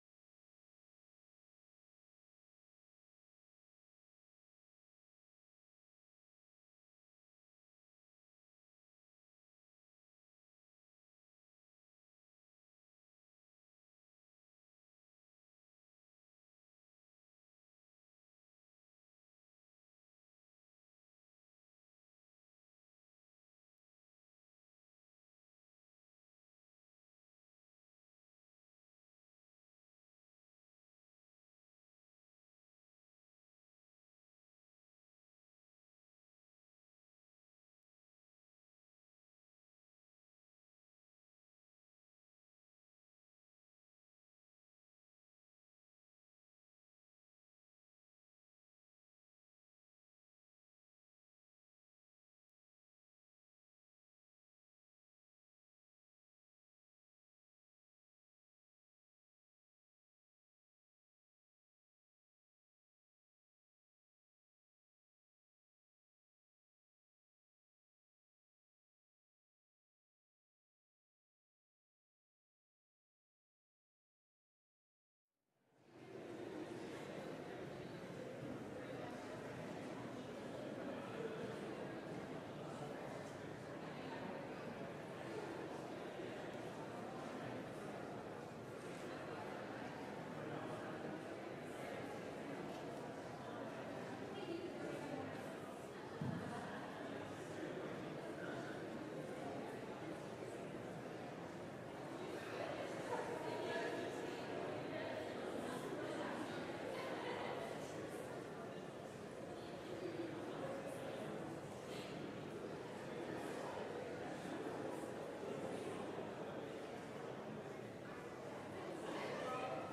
LIVE Morning Worship Service - God's Handiwork
Congregational singing—of both traditional hymns and newer ones—is typically supported by our pipe organ.